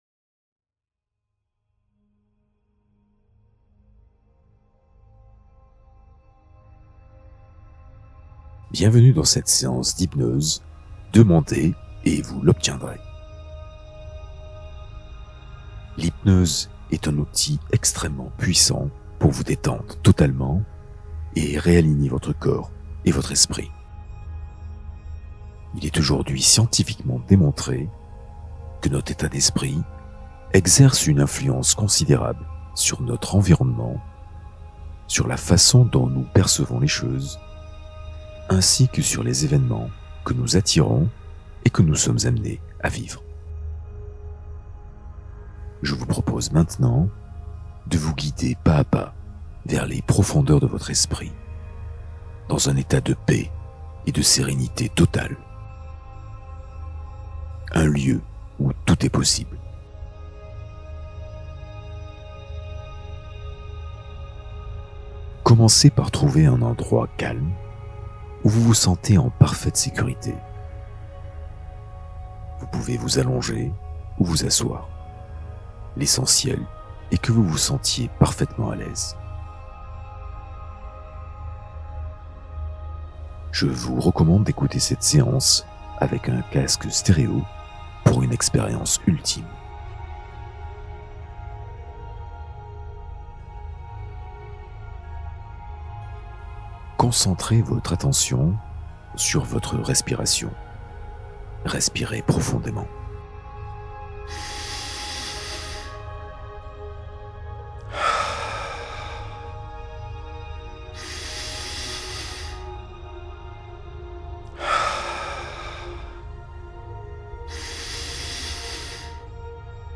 Méditation guidée pour réparer ton ADN et activer la guérison